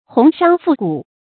鴻商富賈 注音： 讀音讀法： 意思解釋： 豪富的商人。